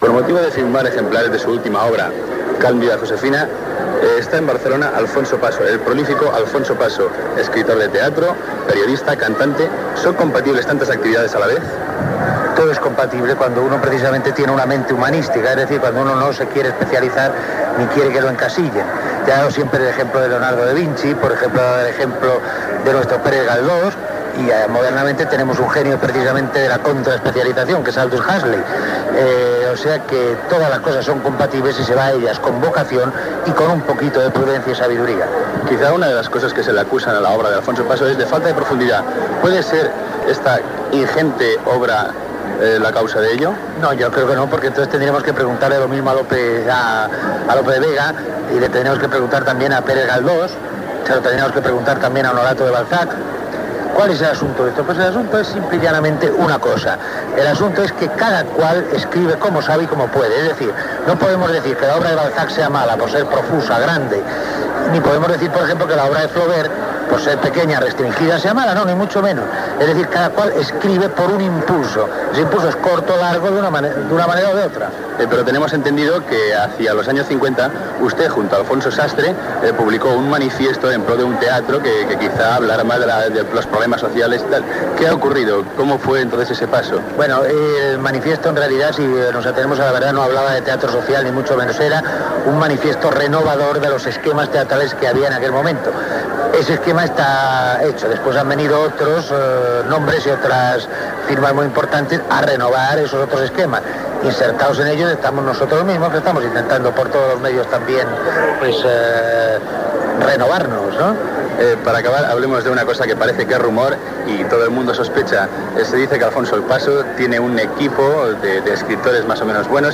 Entrevista al dramaturg Alfonso Paso que presenta a Barcelona el seu llibre "Cálida Josefina".
Informatiu